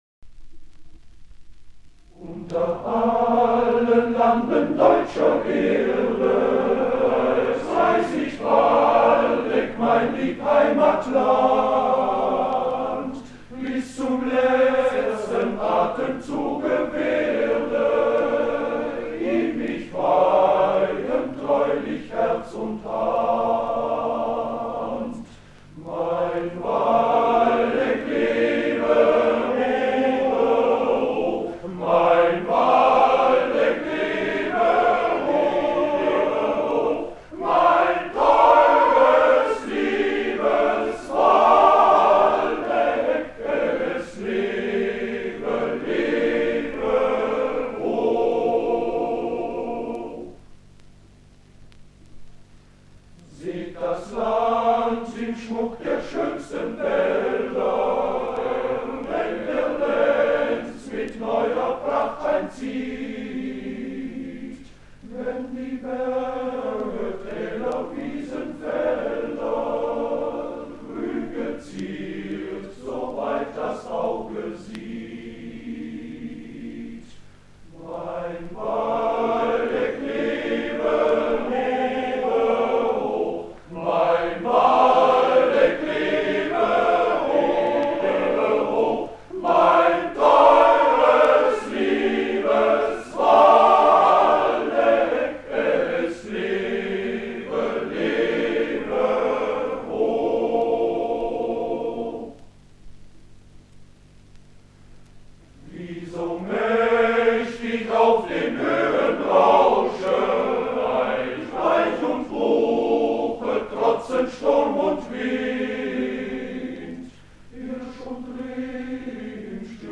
Waldecker Lied, Männerchor Concordia Willingen (1965)